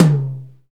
TOM H H HI03.wav